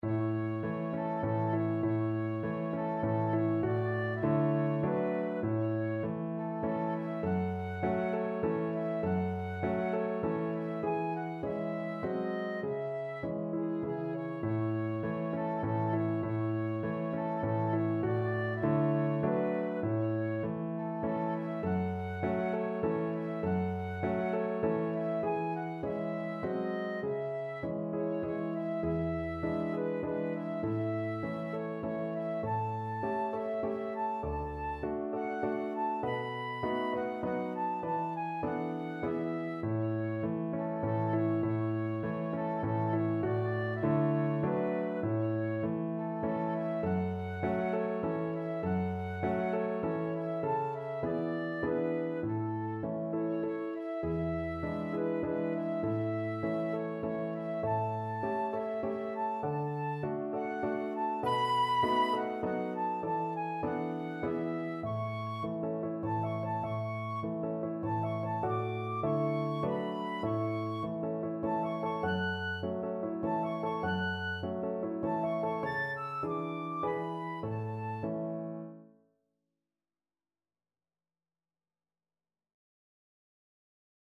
has been arranged for flute and piano.